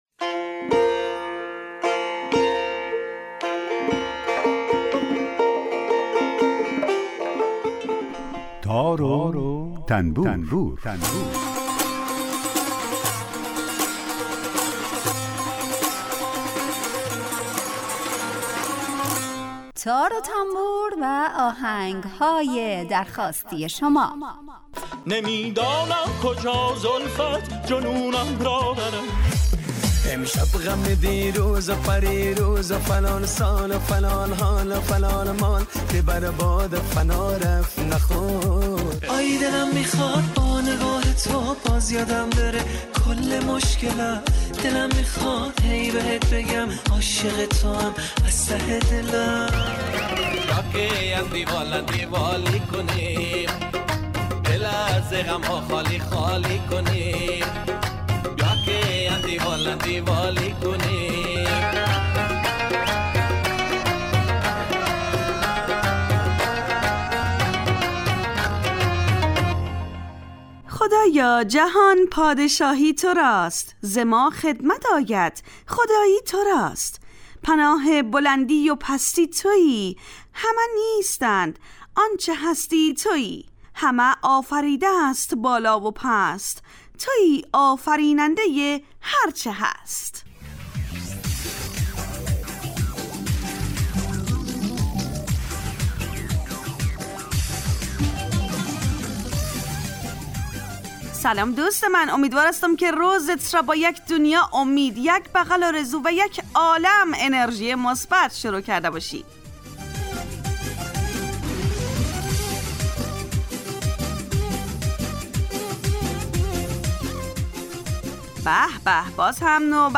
آهنگهای درخواستی